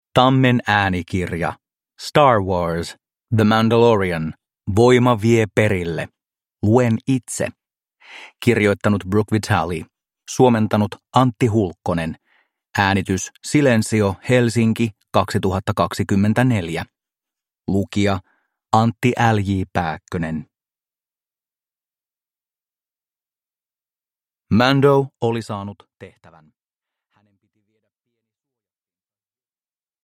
Star Wars. The Mandalorian. Voima vie perille. Luen itse – Ljudbok